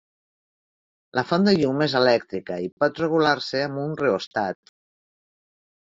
Pronounced as (IPA) [rə.ɣuˈlar]